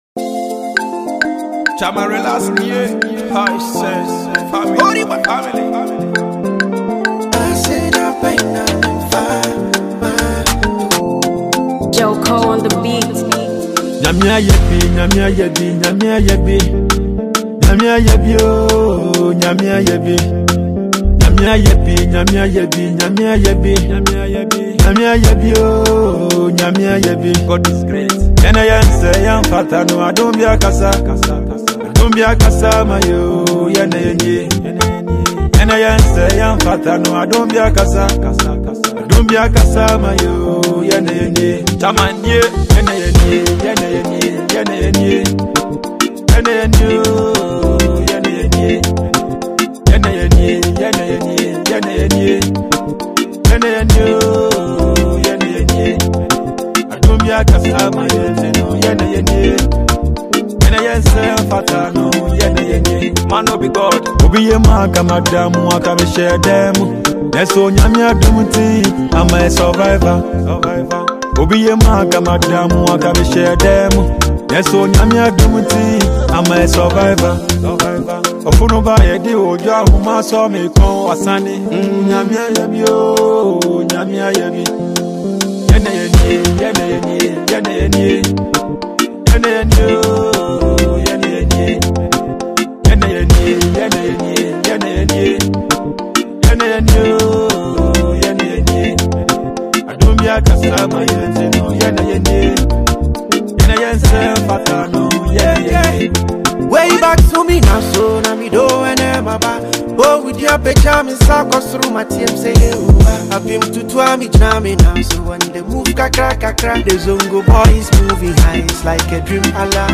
an established Ghanaian Highlife musician.